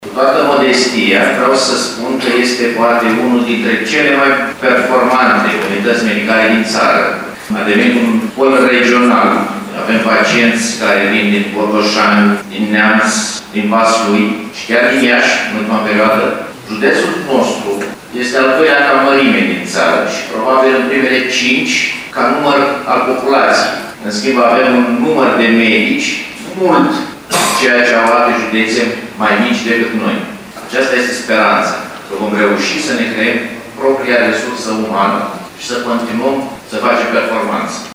El a declarat, la festivitățile dedicate împlinirii a 60 de ani de la înființarea Universității “Ștefan cel Mare”, că această transformare “ar fi o trecere la alt nivel și ar putea reda încrederea populației în actul medical”.